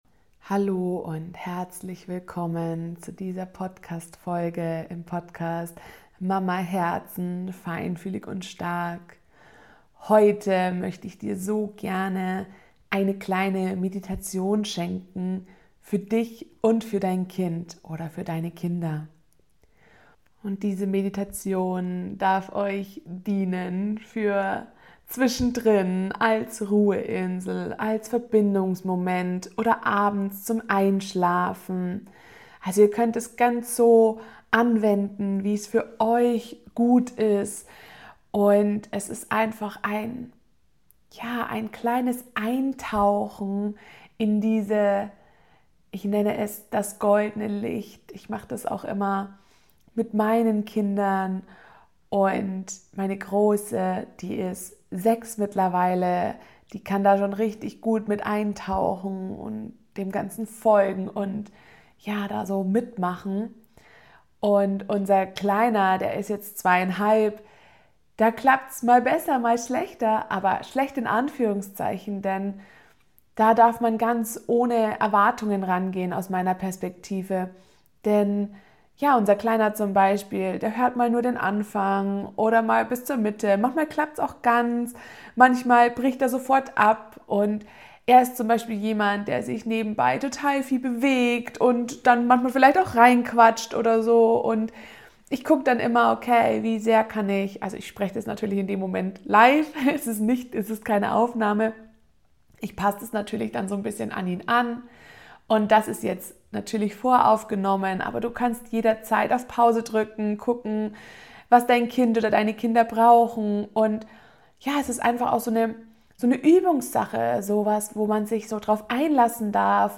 #10: Das goldene Licht - Meditation für Dich und Dein Kind ~ MamaHERZEN - feinfühlig & stark Podcast